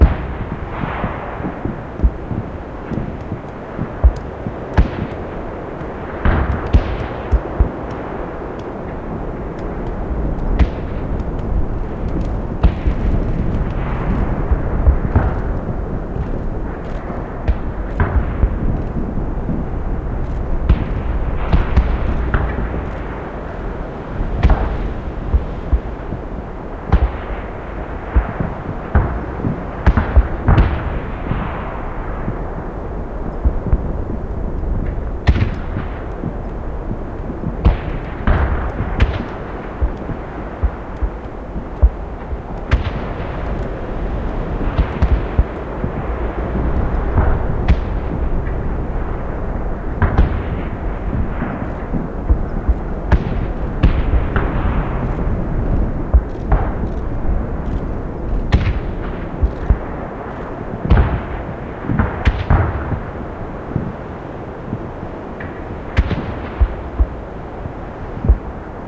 outdoorbattle.ogg